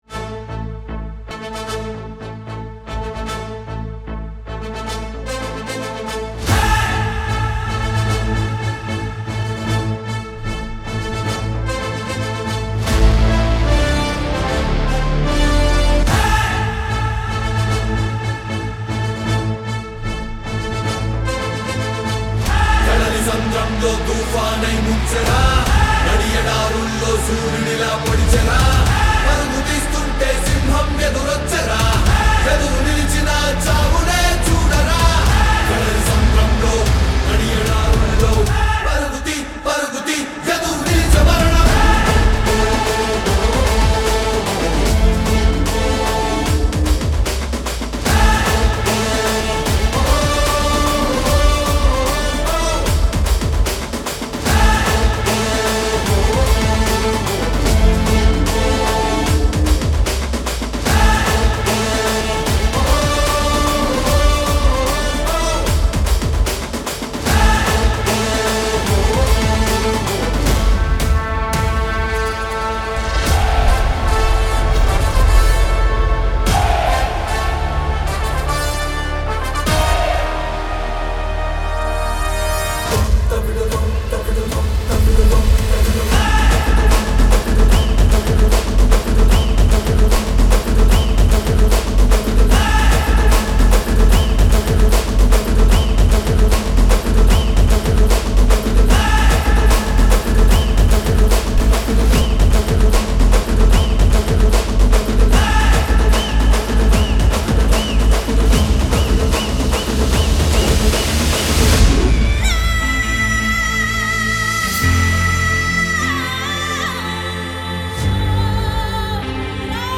without fight sounds